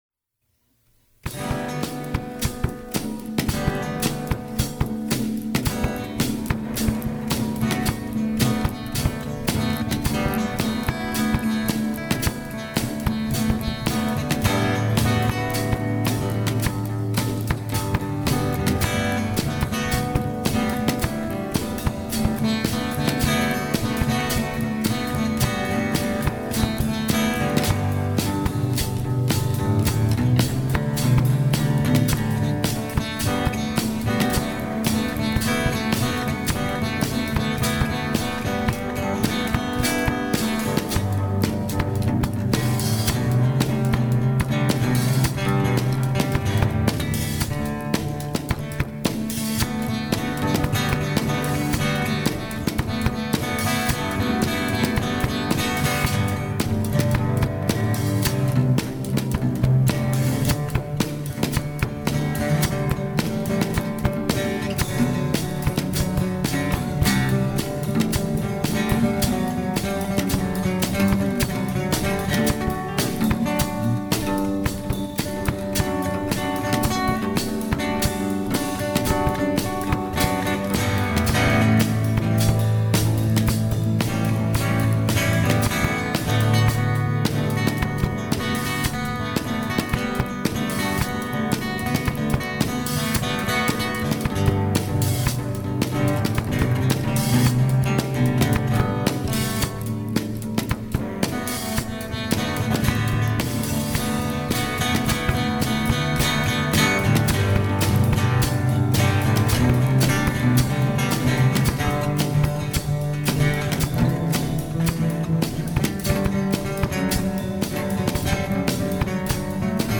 an original improvised instrumental